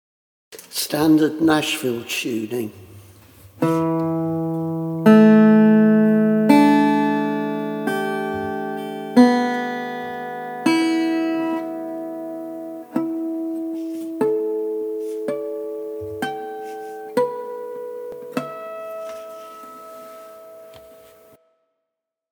Nashville tuning demonstration
7-nashville-tuning.mp3